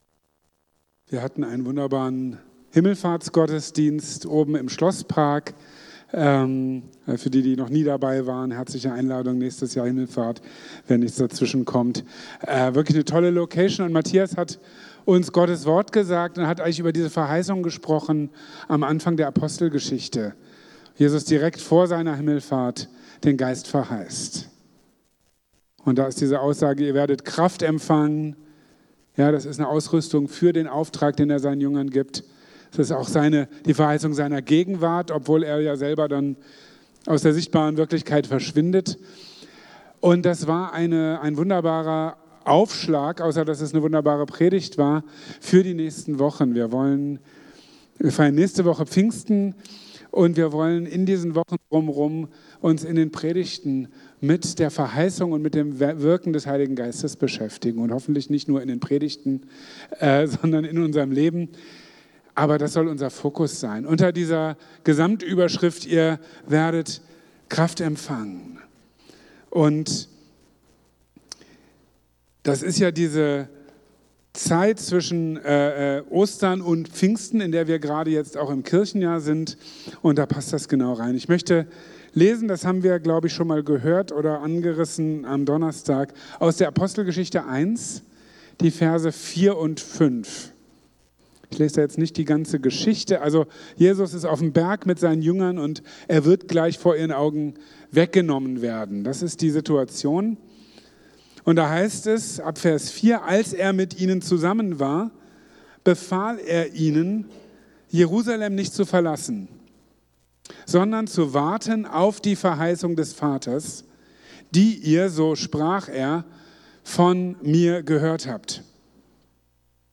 Mit dieser Predigt startet eine neue Predigtreihe über das Wirken des Heiligen Geistes!